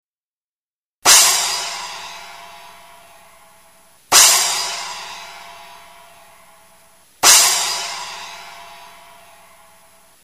ozonesplash.mp3